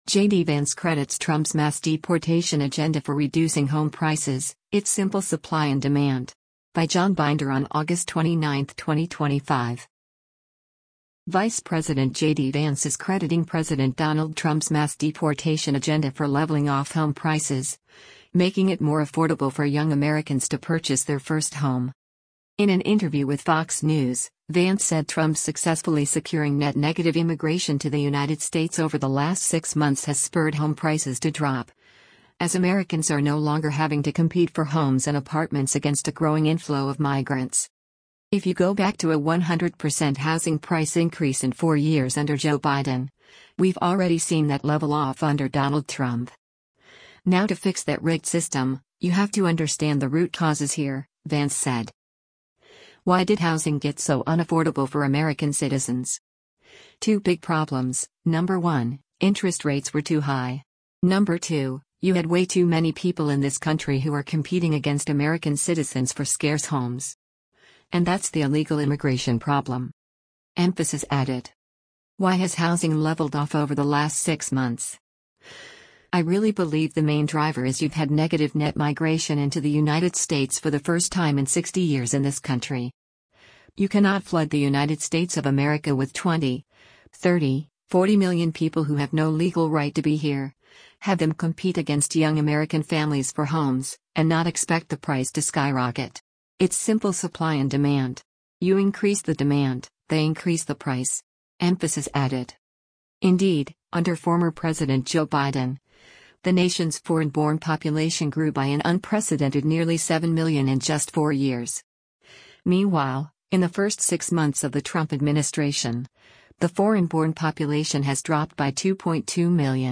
In an interview with Fox News, Vance said Trump’s successfully securing net-negative immigration to the United States over the last six months has spurred home prices to drop, as Americans are no longer having to compete for homes and apartments against a growing inflow of migrants.